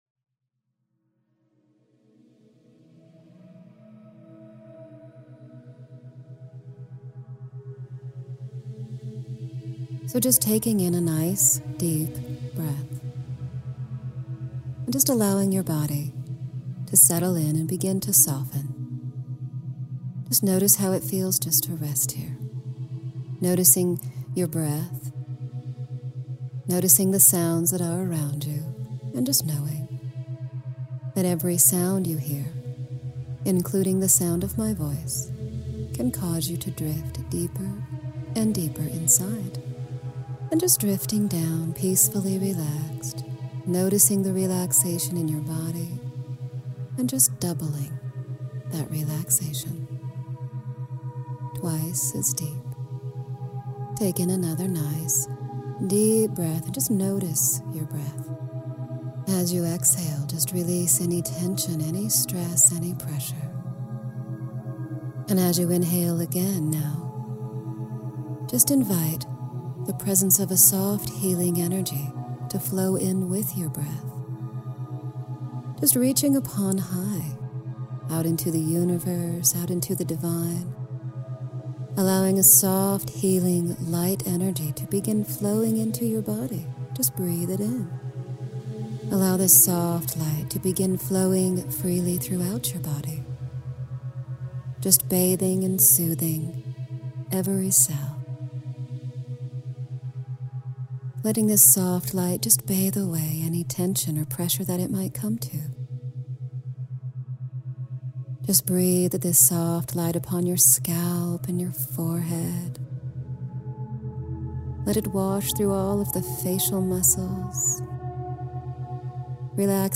Cellular Wisdom Meditation